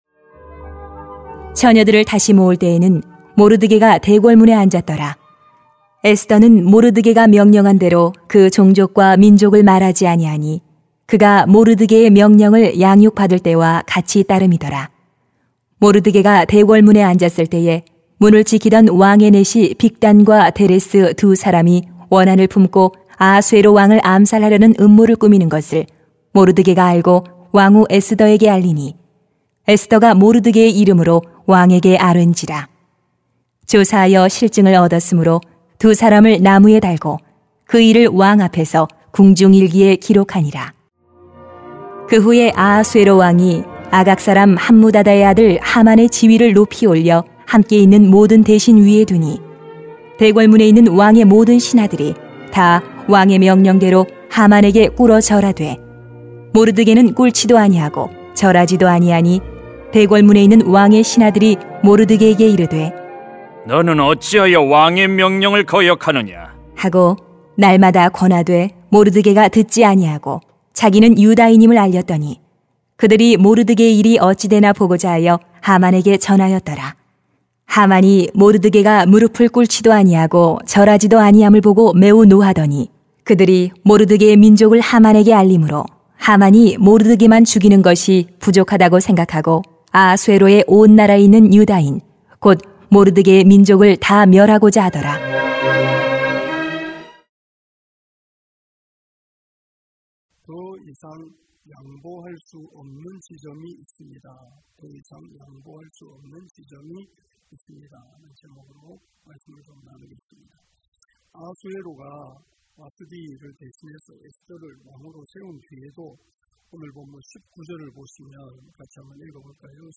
[에 2:19-3:6] 더 이상 양보할 수 없는 지점이 있습니다 > 새벽기도회 | 전주제자교회